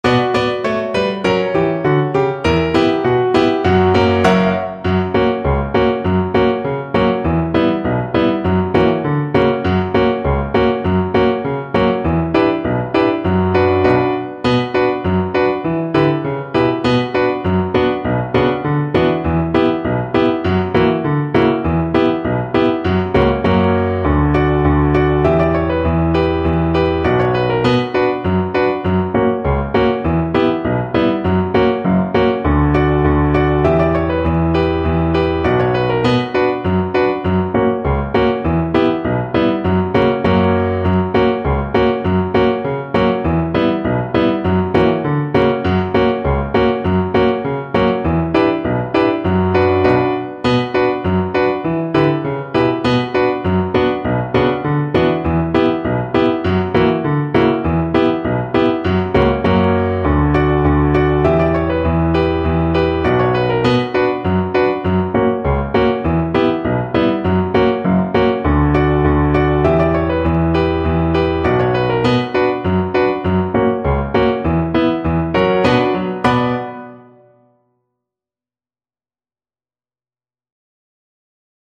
Violin version
Violin
4/4 (View more 4/4 Music)
B minor (Sounding Pitch) (View more B minor Music for Violin )
Allegretto
Traditional (View more Traditional Violin Music)
world (View more world Violin Music)